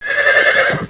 Heroes3_-_Unicorn_-_HurtSound.ogg